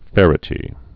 (fĕrĭ-tē)